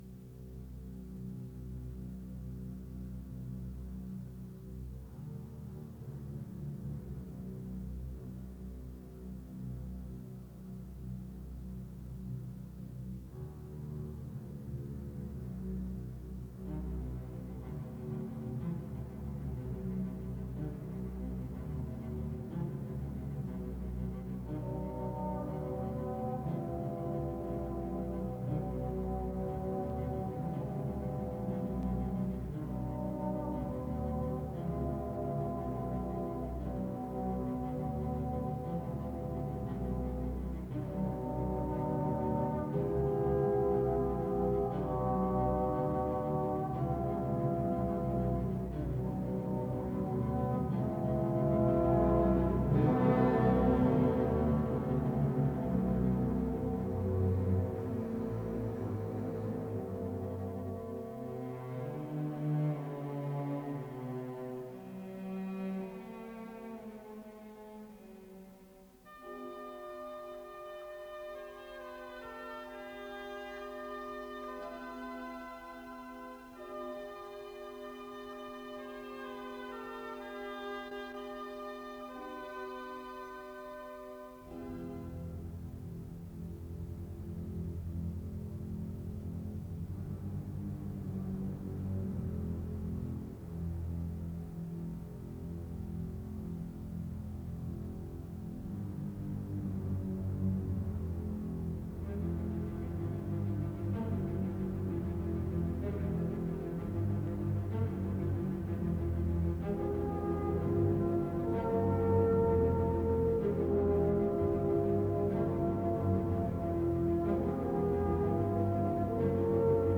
Исполнитель: Государственный академический симфонический оркестр